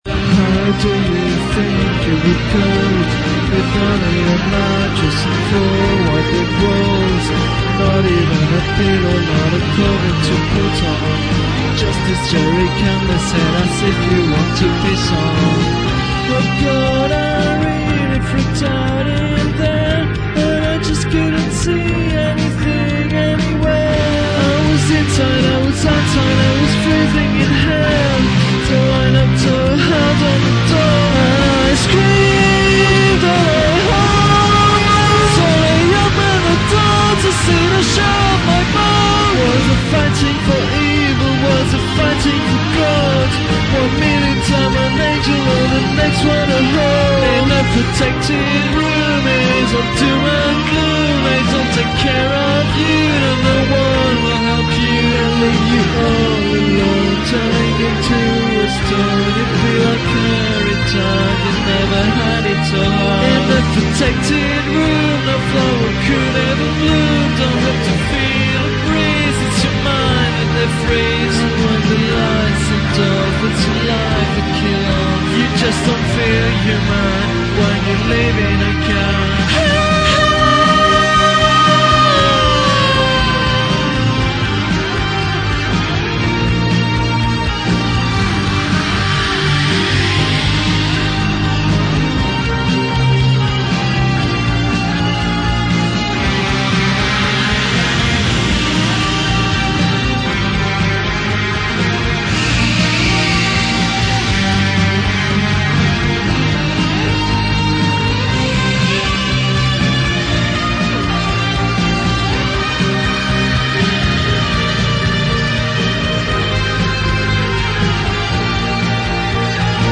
OK, let's be fair, I think the singing is atrocious, the music mostly rubbish, and the lyrics cringeworthy.